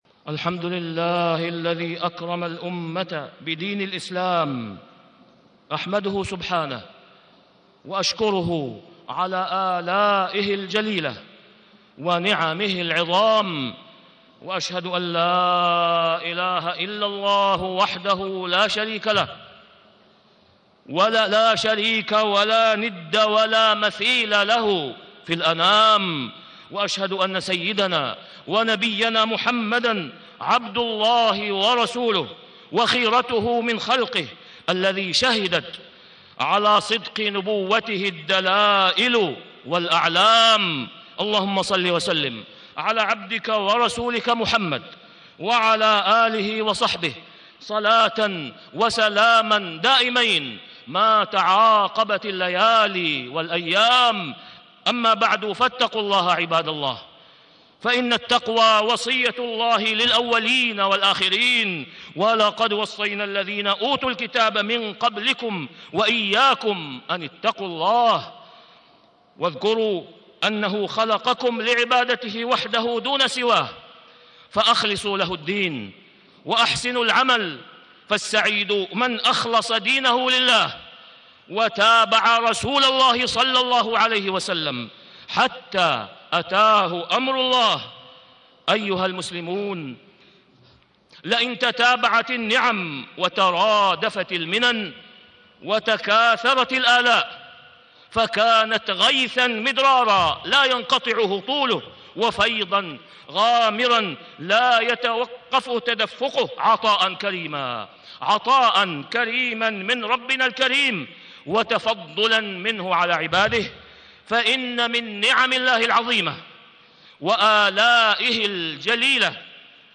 تاريخ النشر ٧ جمادى الآخرة ١٤٣٦ هـ المكان: المسجد الحرام الشيخ: فضيلة الشيخ د. أسامة بن عبدالله خياط فضيلة الشيخ د. أسامة بن عبدالله خياط إجابة نداء اليمن